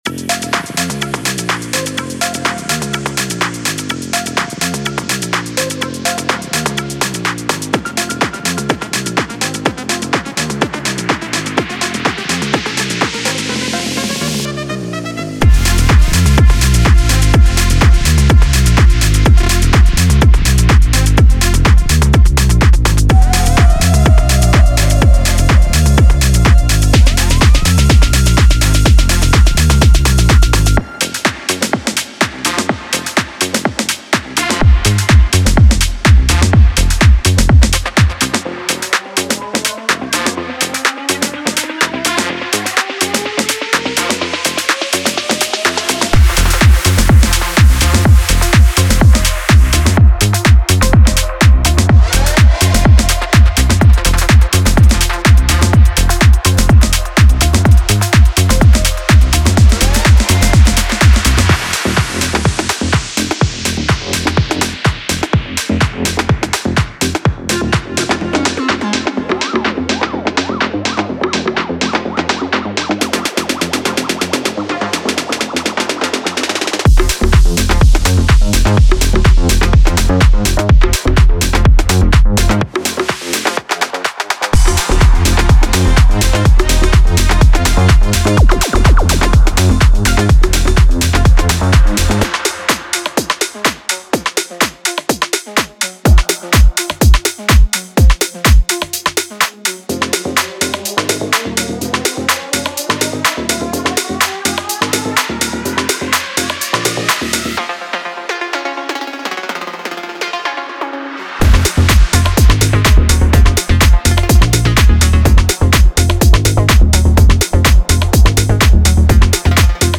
Genre:Tech House
デモサウンドはコチラ↓